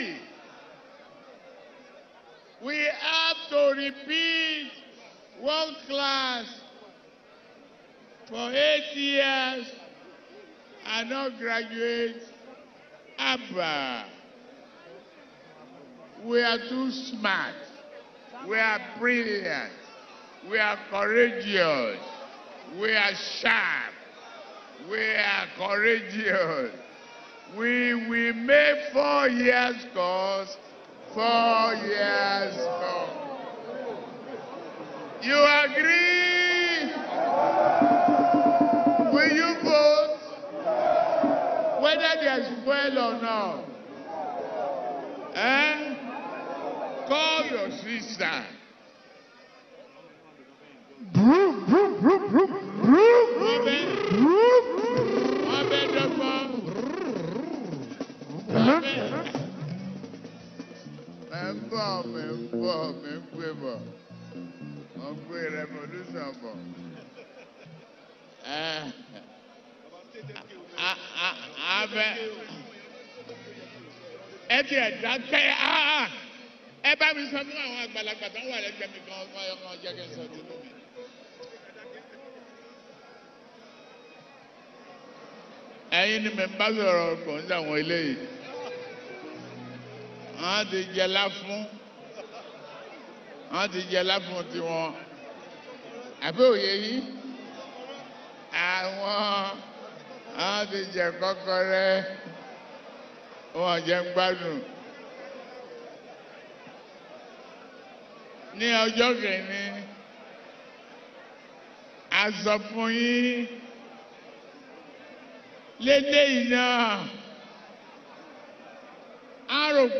Nigerian Yoruba Fuji track
Yoruba Fuji Sounds
be ready to dance to the beats